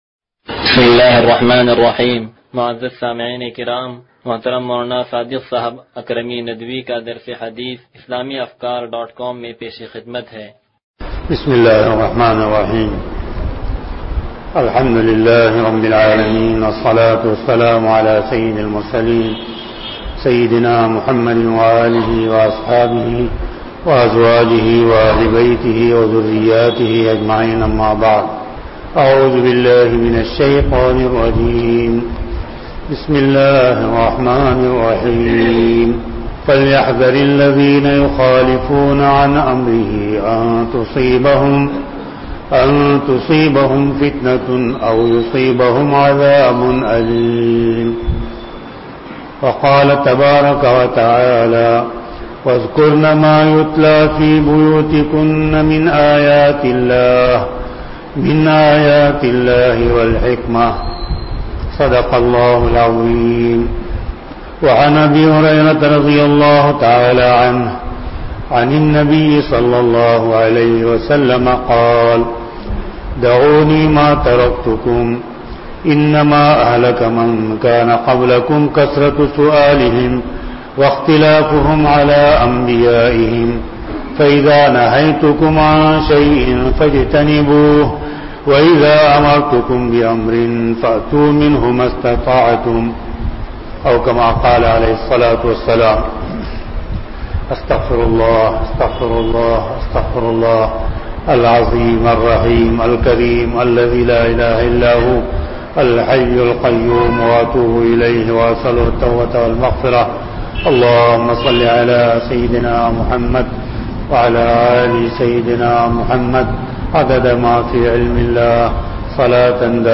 درس حدیث نمبر 0160